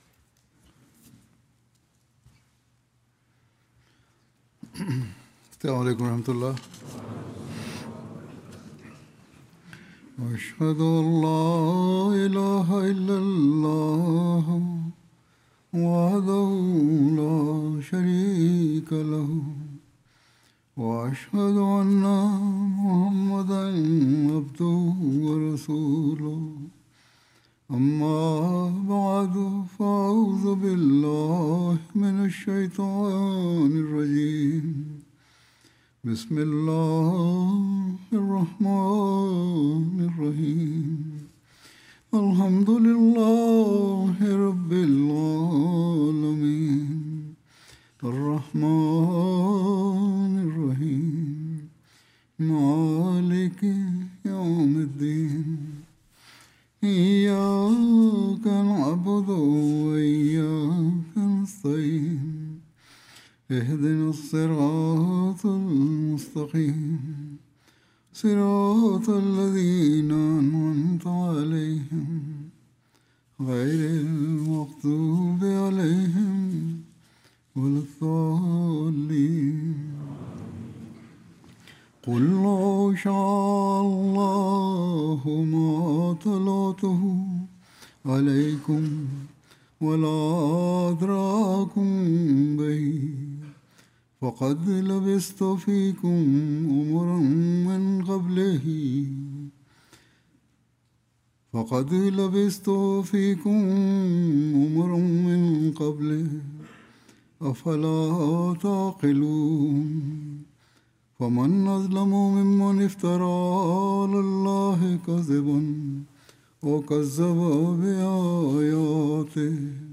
17 April 2026 The Prophet (sa): Al-Sadiq wa Al-Amin: The Truthful and The Trustworthy Urdu Friday Sermon by Head of Ahmadiyya Muslim Community 45 min About Urdu Friday Sermon delivered by Khalifa-tul-Masih on April 17th, 2026 (audio)